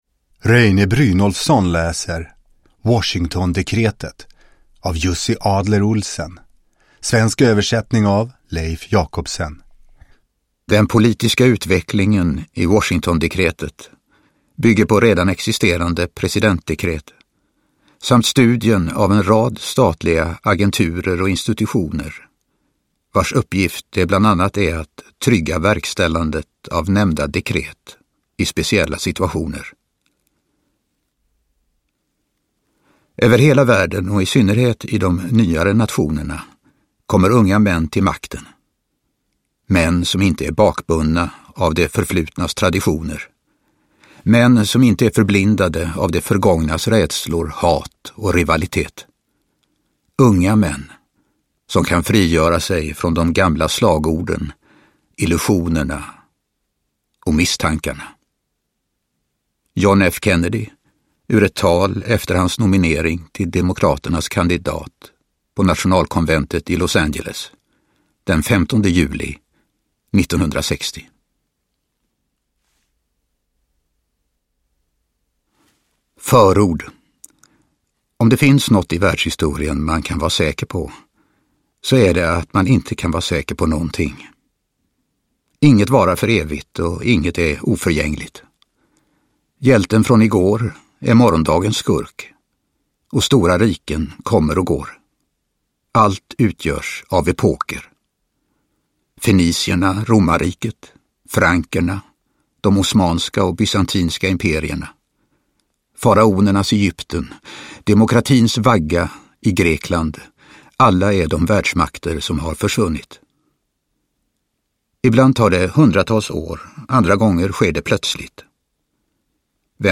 Washingtondekretet – Ljudbok – Laddas ner
Uppläsare: Reine Brynolfsson